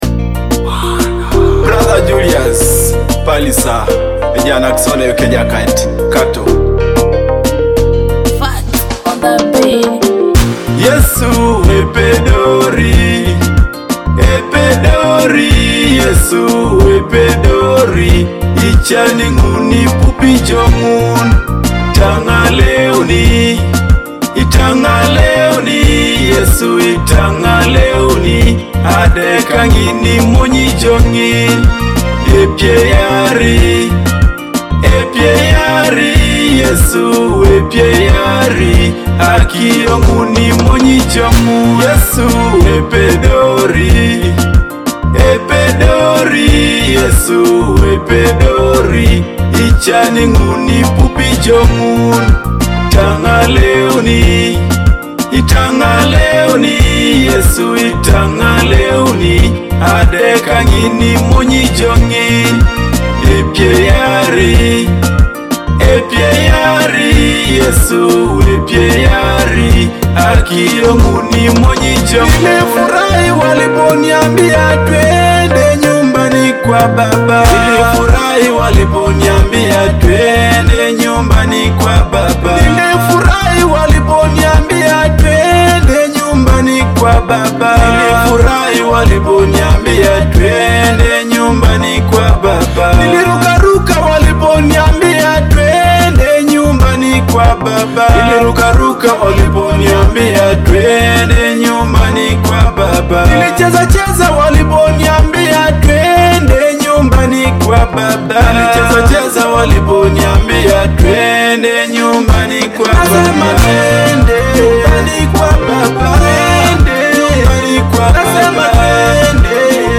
a powerful gospel praise song declaring Jesus is able.
Experience the powerful gospel praise and worship anthem